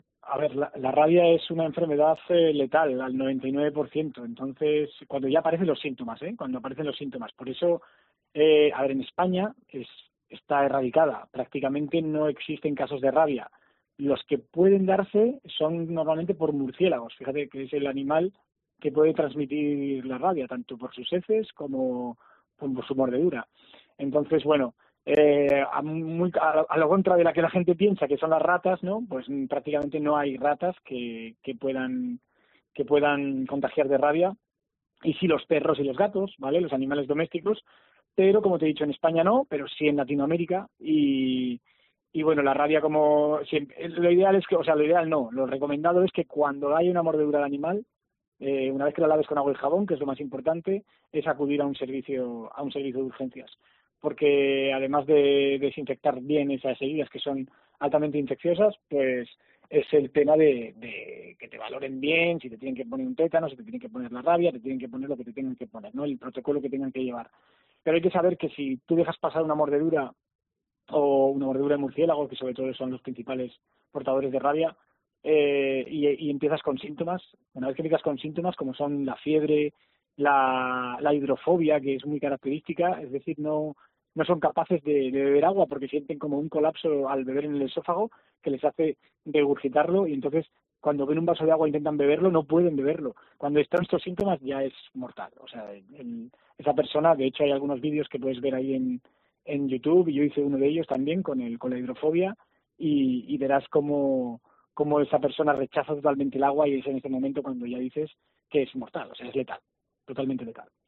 En Poniendo las Calles nos ponemos en alerta ante la posibilidad de que la mordedura de una animal produzca un contagio de este tipo con